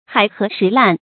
海涸石爛 注音： ㄏㄞˇ ㄏㄜˊ ㄕㄧˊ ㄌㄢˋ 讀音讀法： 意思解釋： 猶海枯石爛。